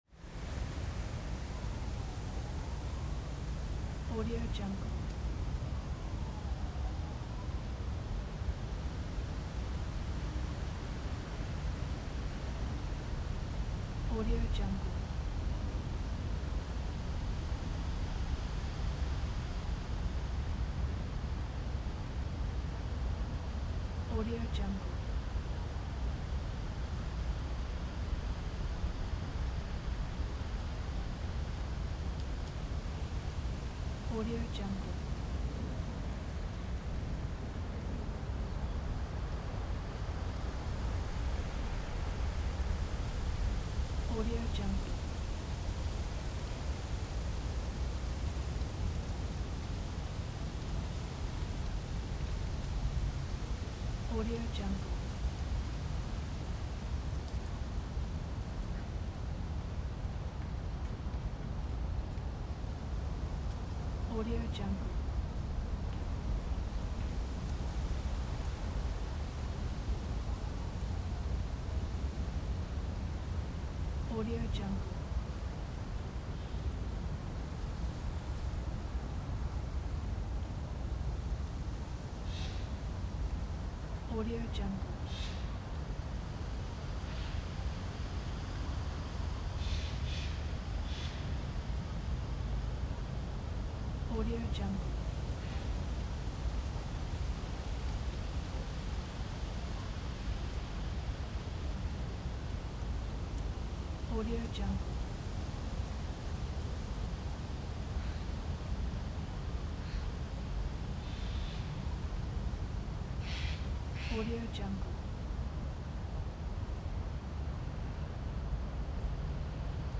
دانلود افکت صوتی باد پاییزی در جنگل حومه شهر 1